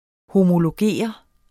homologere verbum Bøjning -r, -de, -t Udtale [ homoloˈgeˀʌ ] Oprindelse jævnfør homologi Betydninger 1.